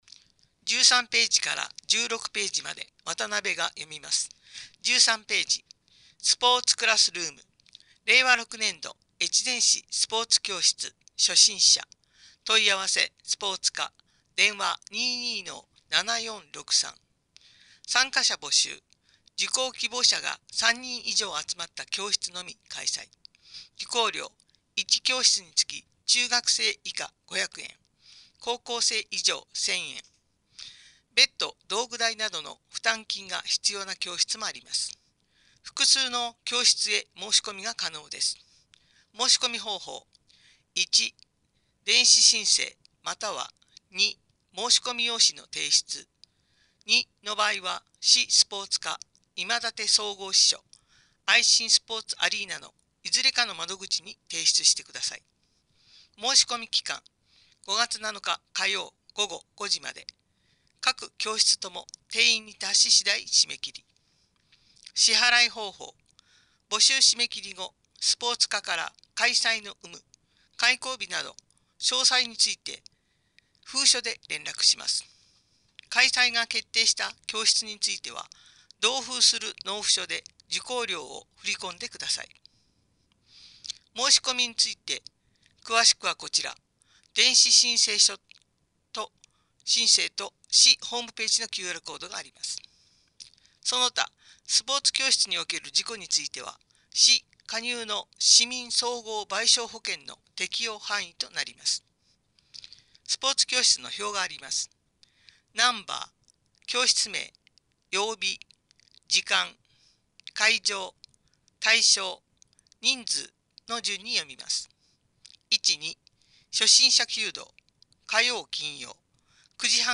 ※越前市広報の音訳は音訳ボランティア「きくの会」の皆さんのご協力のもと配信しています。